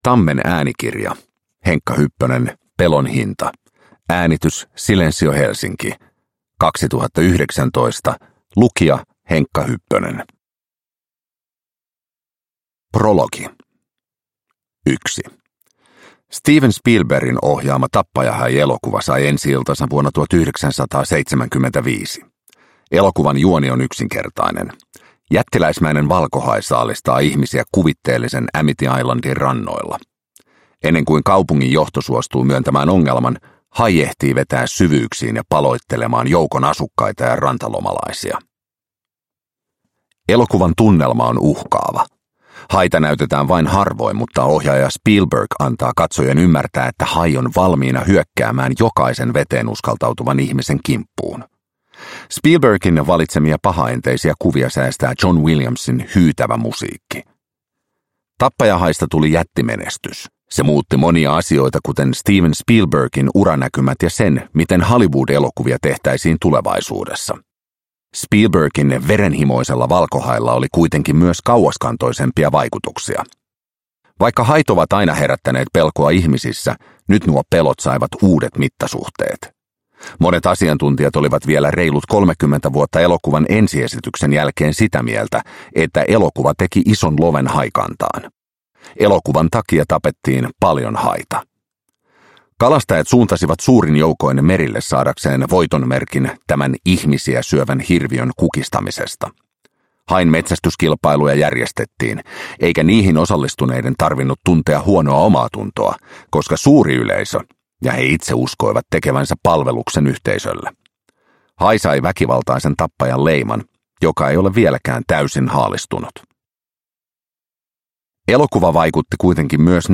Pelon hinta – Ljudbok – Laddas ner